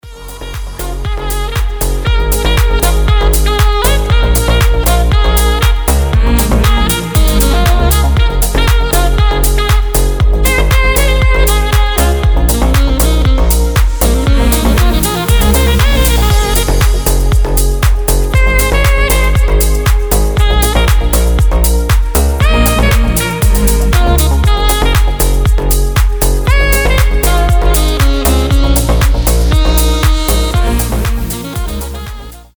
• Качество: 320, Stereo
deep house
без слов
красивая мелодия
Саксофон
Дипчик с саксофоном